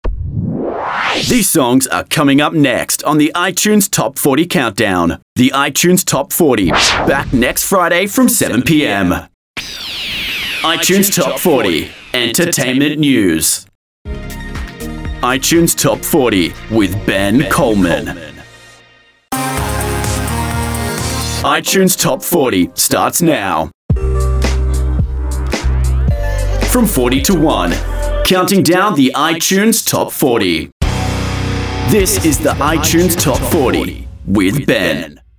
Youthful, Character work, Natural Energy Male Voice Over Talent